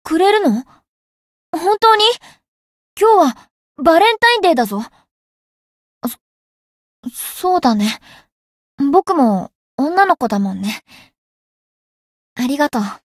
灵魂潮汐-莉莉艾洛-情人节（送礼语音）.ogg